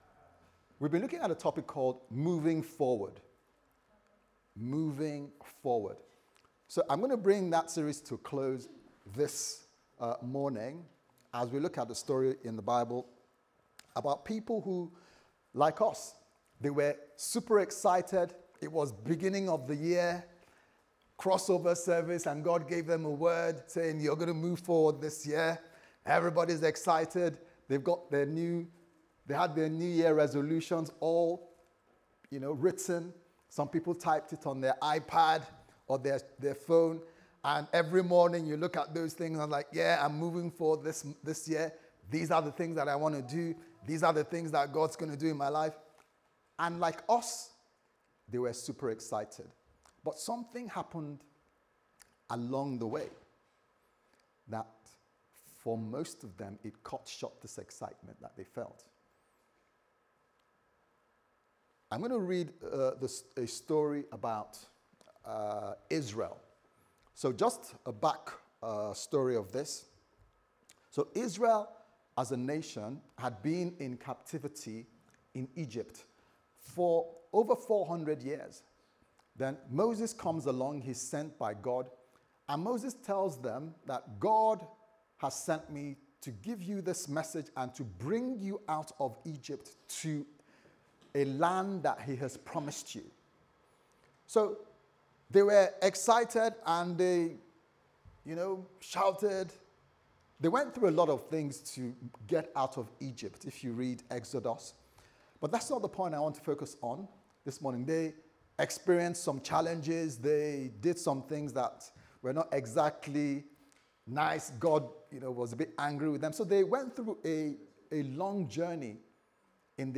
Sunday Service Sermon « CFT Bristol Co-ordinators Charge Is Love Enough?